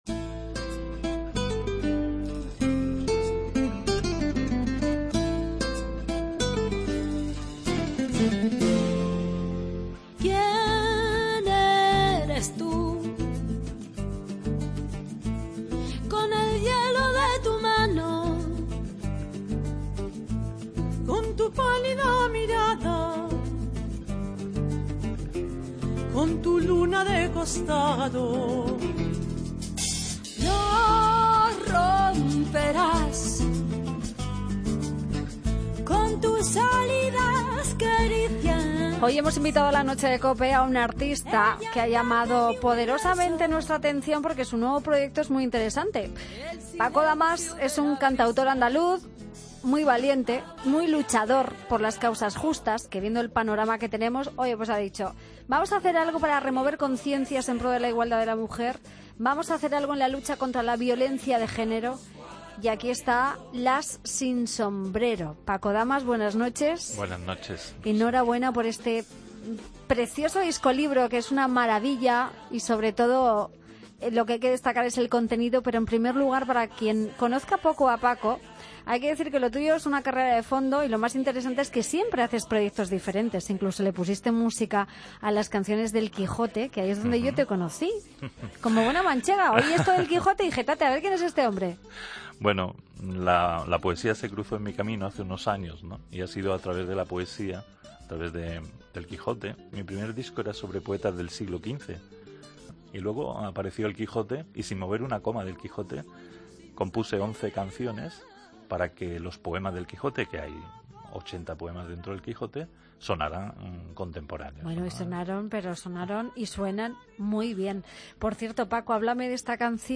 'Las Sin Sombrero' es un disco lleno de canciones a favor de los derechos de las mujeres. ESCUCHA LA ENTREVISTA COMPLETA